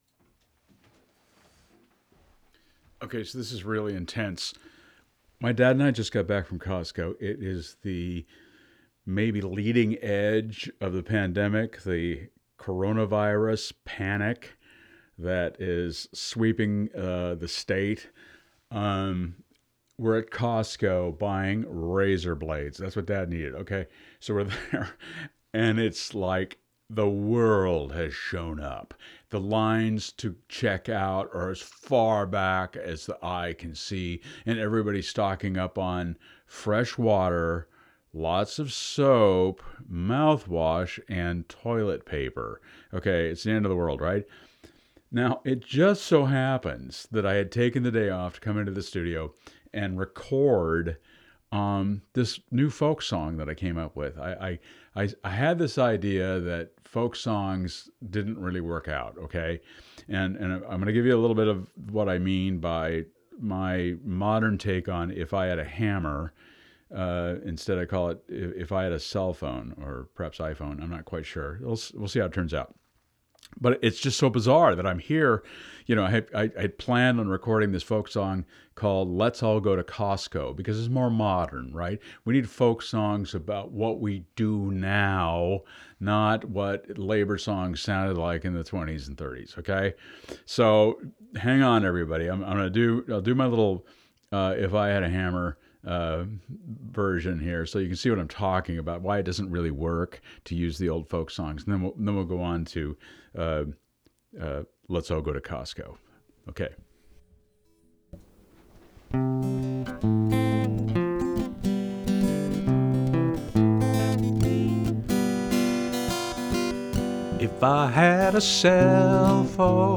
I picked up the guitar and started singing all those old folk songs American’s love, like The Hammer Song etc. I realized they were all all labor songs from an era that has passed.
Maybe that will be the topic of your next folk song 🙂